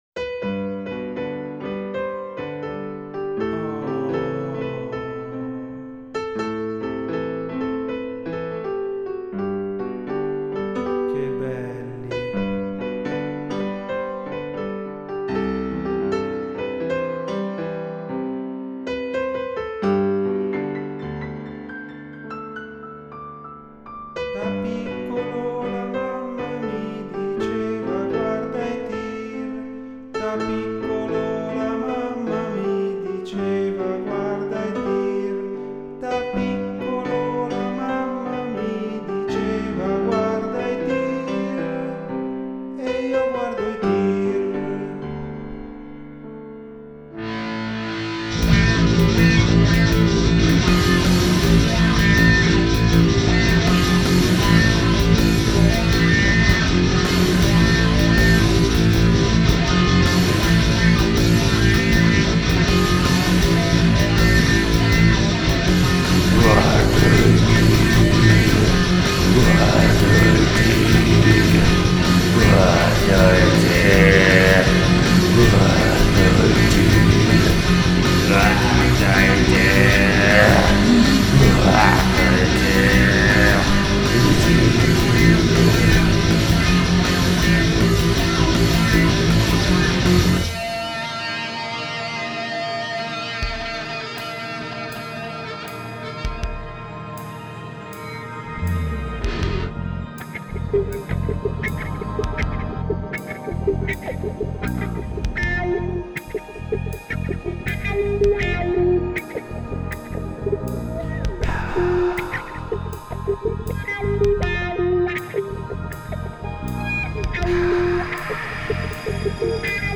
vocina
vocetta sofisticata